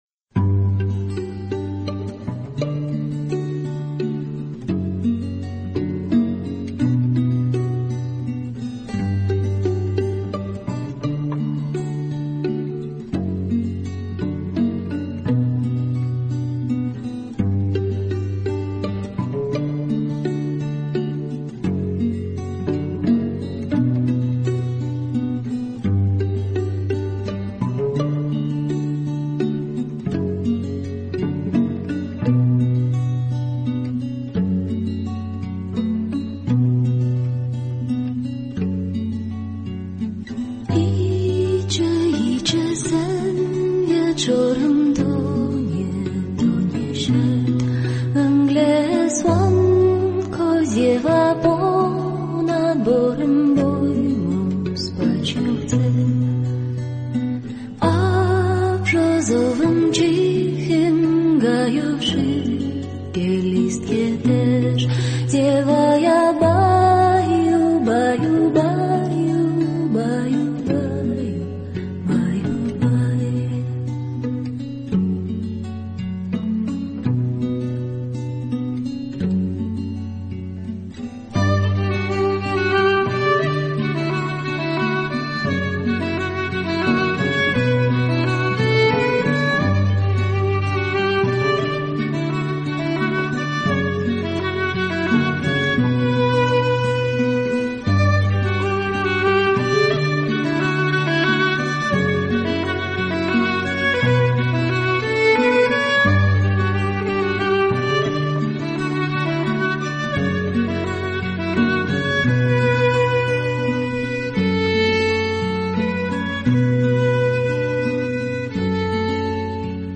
Колыбельная на польском 🇵🇱